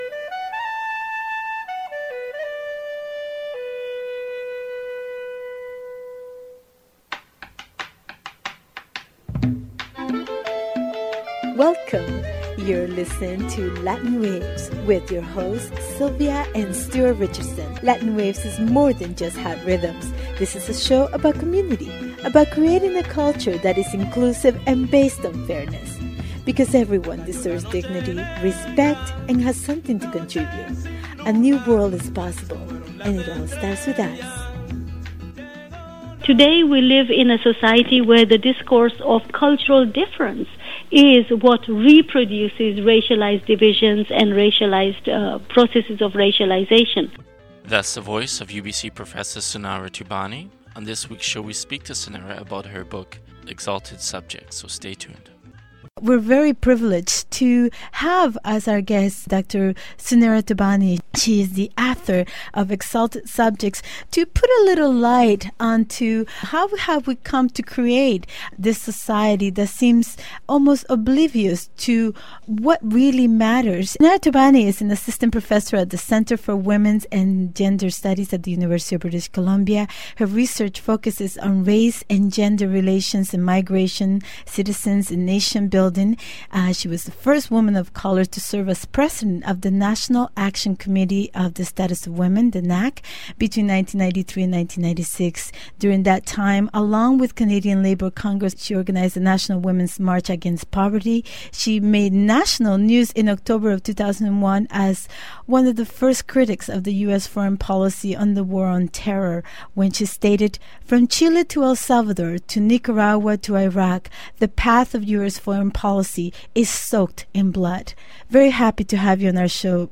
community radio exchange